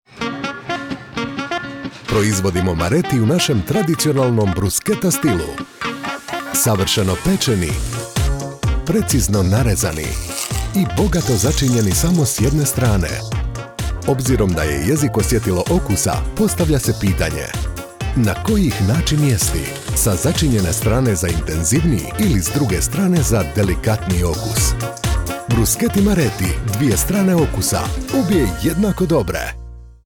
Promos
I am a professional Croatian voice-over artist, speaker, narrator and producer with over 20 years of experience with my own studio.
Middle-Aged
BassDeep
WarmAuthoritativeConversationalCorporateFriendly